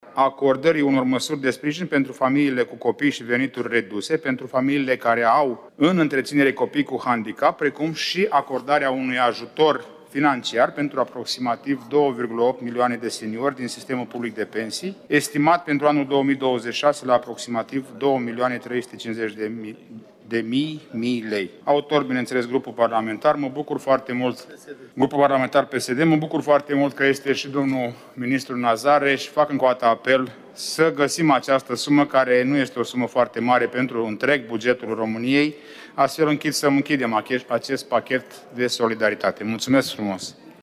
Continuă dezbaterile în comisiile reunite de buget–finanțe: Aleșii discută acum bugetul pentru Ministerul Muncii. Moment în care PSD a anunțat amendamentele pentru pachetul de solidaritate – ajutoare pentru vârstnicii cu venituri mici și persoanele vulnerabile.
Deputatul PSD, Marius Budăi: „Nu este o sumă foarte mare pentru întreg bugetul României”